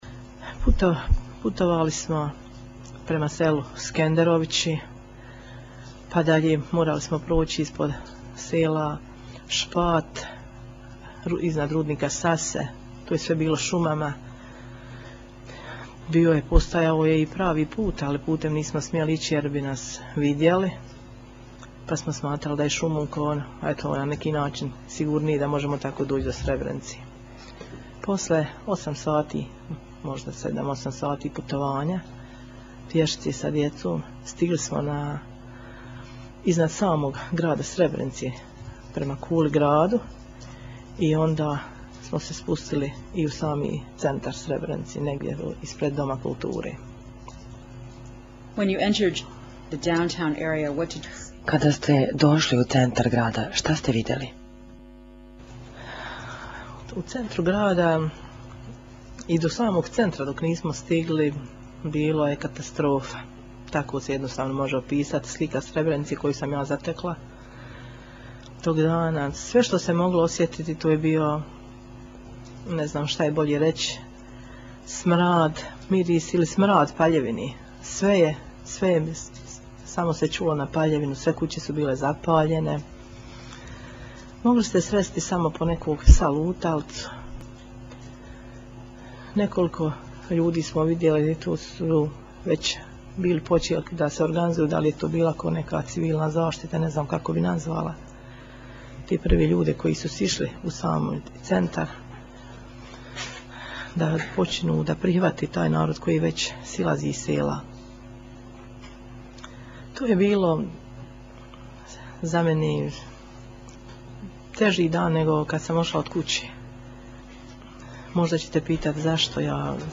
Svjedokinja o dolasku u Srebrenicu